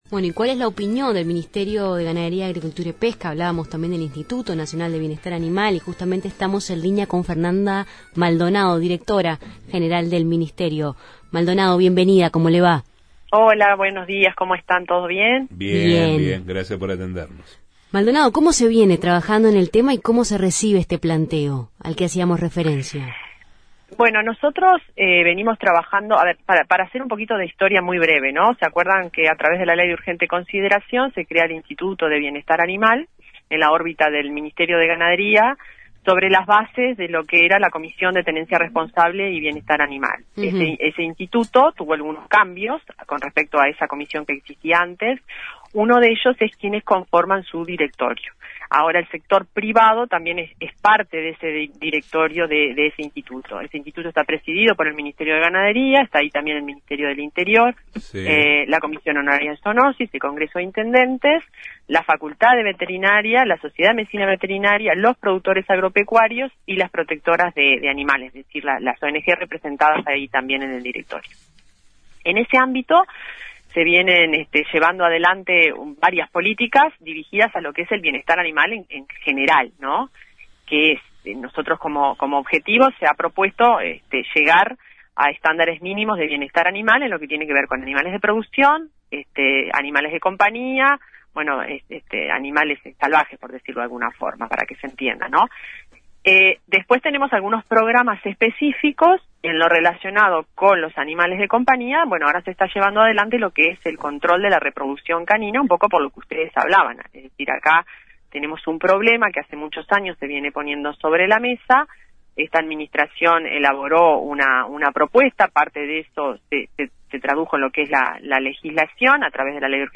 Así lo afirmó en Justos y pecadores Fernanda Maldonado, directora general del MGAP sobre bienestar animal